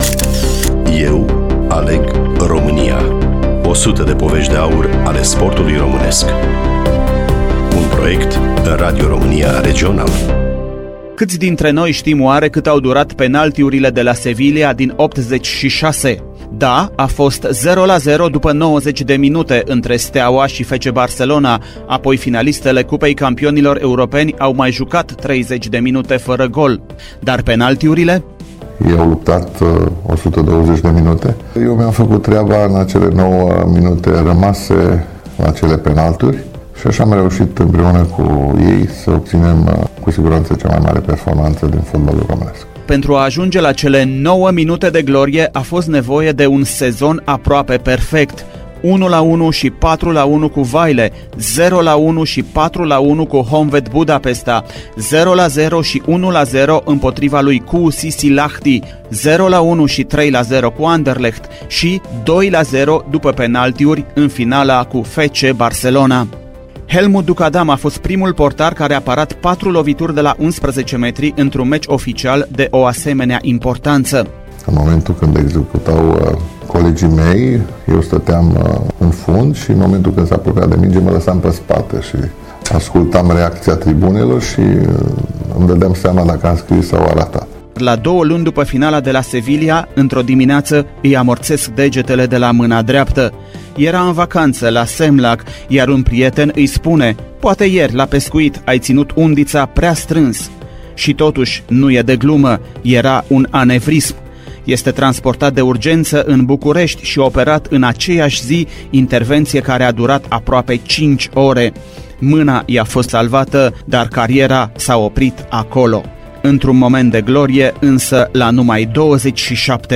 Studioul Radio România Timişoara
Realizator / voiceover: